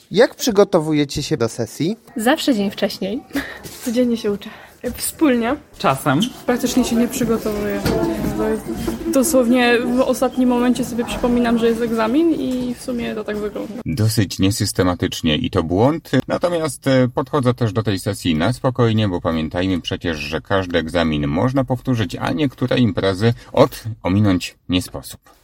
Sonda-sesja-zimowa.mp3